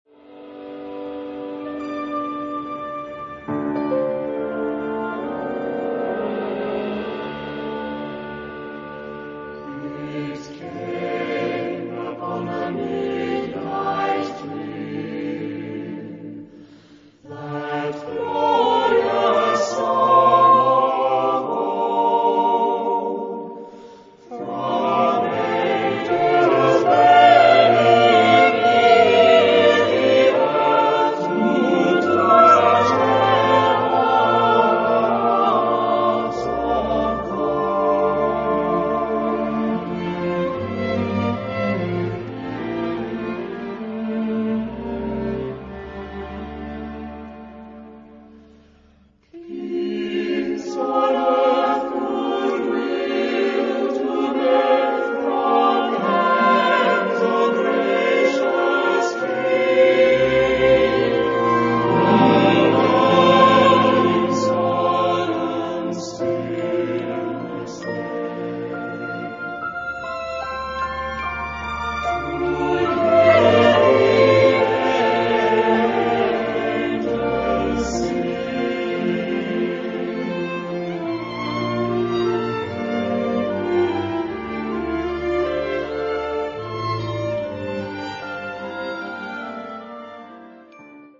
Genre-Stil-Form: geistlich ; weltlich ; Weihnachtslied
Charakter des Stückes: mäßig
Chorgattung: SSAATTBB  (8-stimmiger gemischter Chor )
Instrumentation: Kammerorchester  (13 Instrumentalstimme(n))
Tonart(en): F-Dur